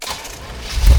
car-engine-start-1.ogg